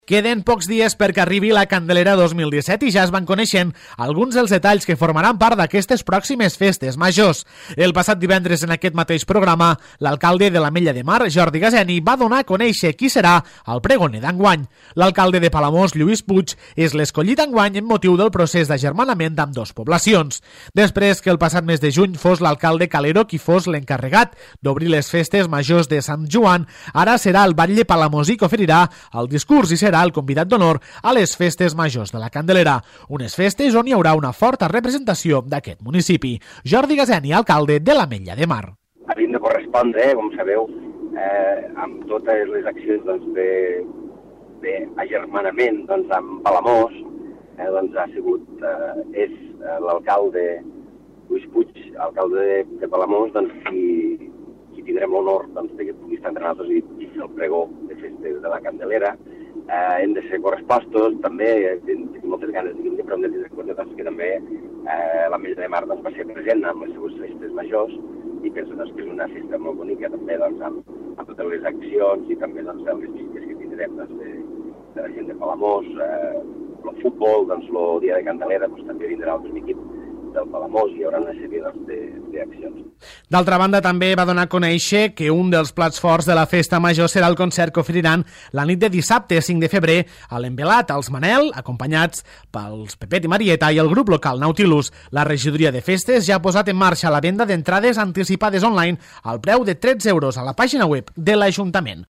El passat divendres a La Cala Ràdio, l'alcalde de l'Ametlla de Mar, Jordi Gaseni, va donar a conèixer qui serà el pregoner d'enguany. L'escollit enguany és l'alcalde de Palamós, Lluís Puig, amb motiu del procés d'agermanament d'ambdós municipis.